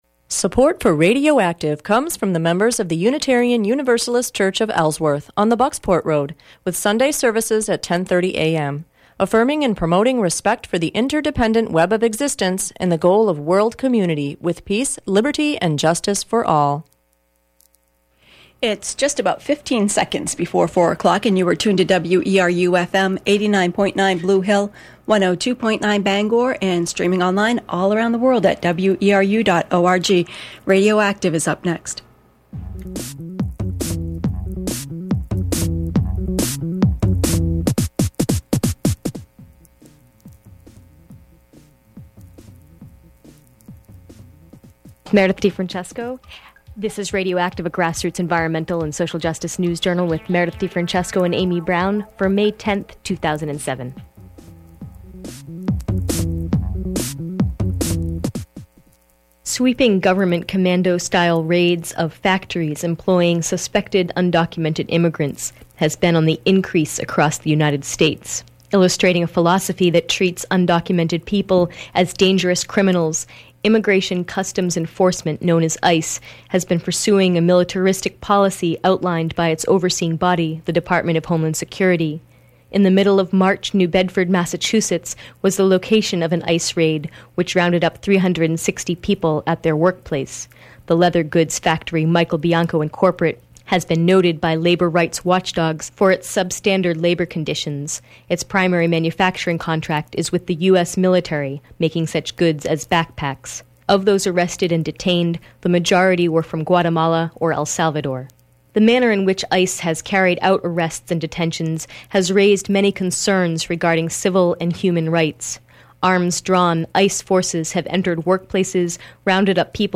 Also, an interview